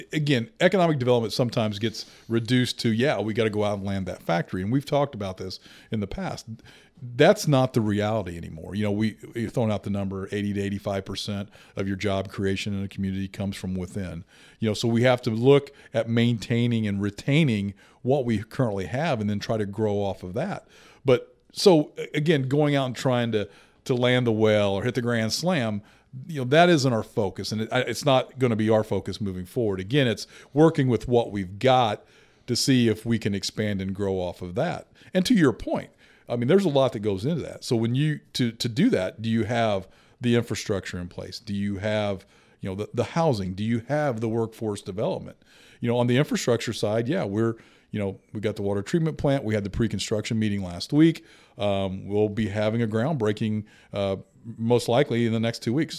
Mayor Knebel says several factors go into economic development–include infrastructure, housing and work force–and he says the committee he’s putting together should be able to look at each factor deeper.